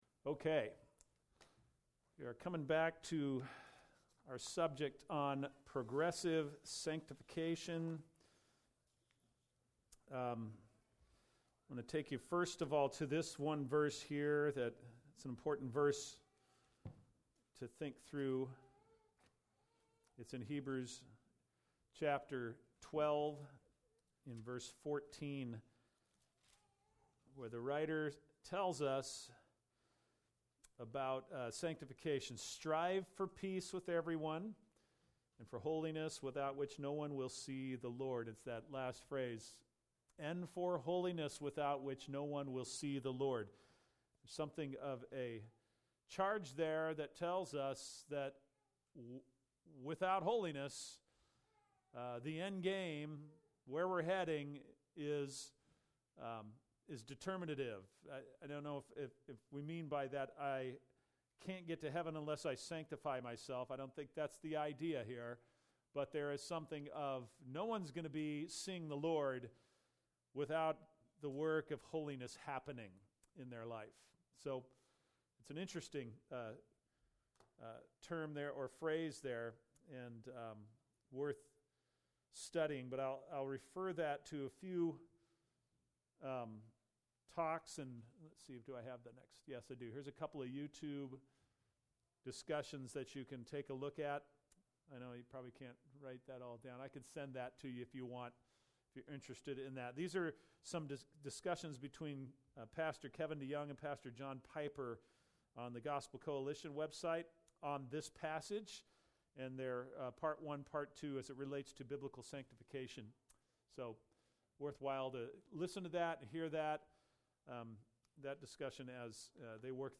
Sunday School http